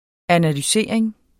Udtale [ analyˈseɐ̯ˀeŋ ]